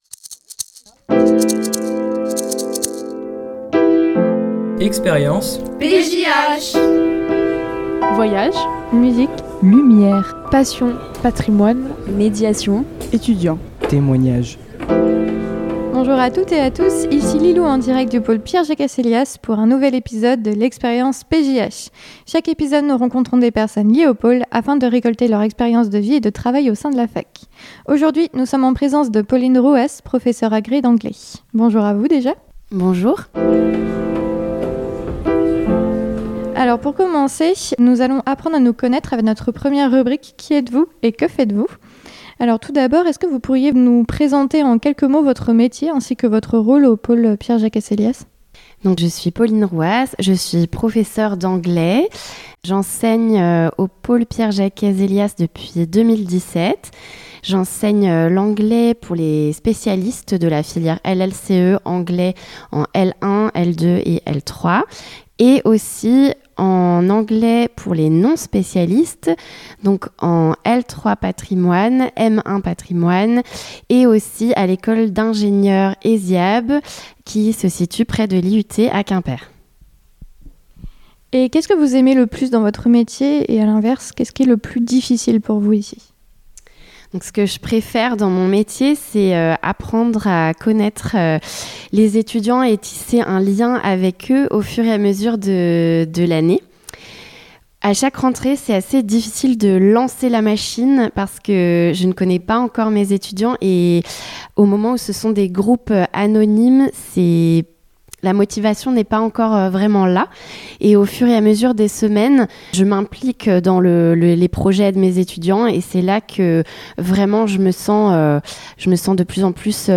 Dans sa chaque épisode, nous rencontrons des personnes liés au pôle afin de récolter leur expérience de vie et de travail au sein de la fac de Quimper.